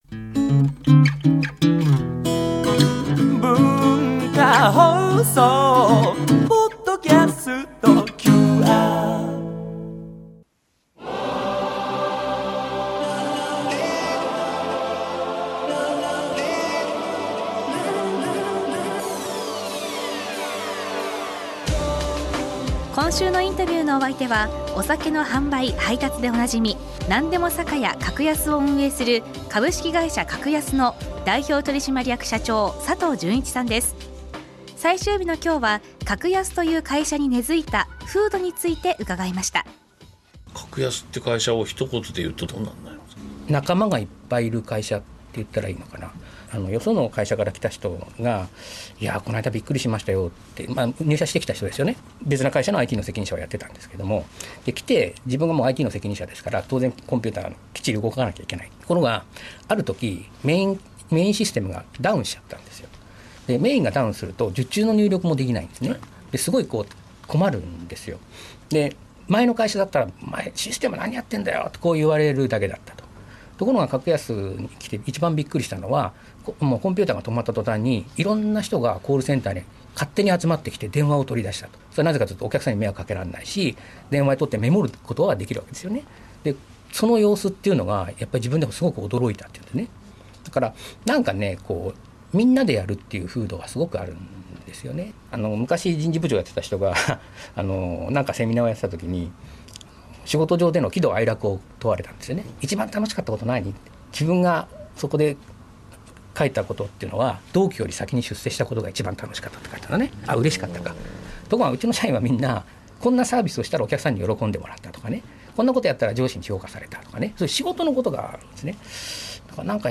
毎週、現代の日本を牽引するビジネスリーダーの方々から次世代につながる様々なエピソードを伺っているマスターズインタビュー。